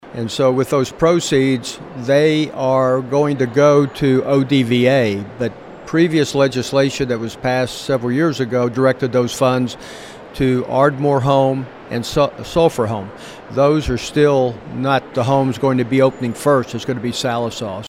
CLICK HERE to listen to commentary from the Director of the State Department of Affairs, Greg Slavonic.